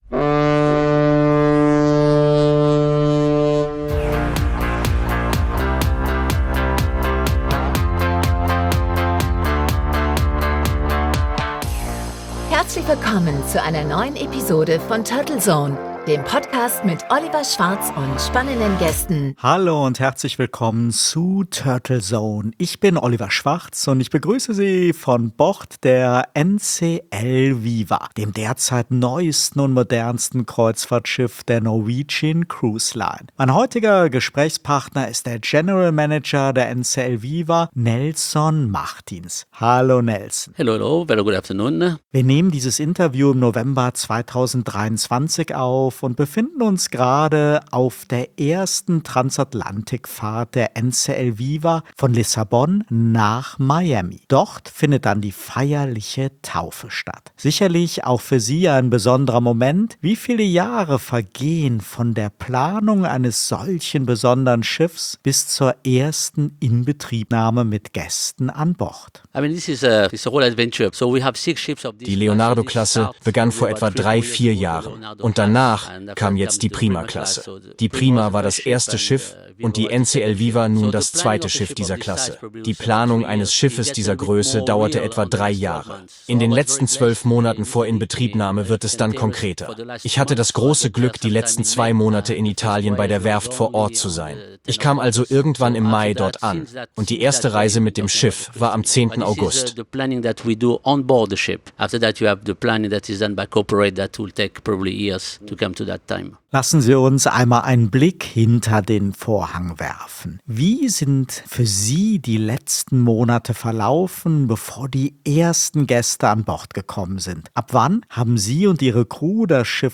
Der Interview-Podcast